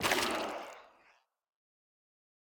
Minecraft Version Minecraft Version latest Latest Release | Latest Snapshot latest / assets / minecraft / sounds / block / sculk_shrieker / break2.ogg Compare With Compare With Latest Release | Latest Snapshot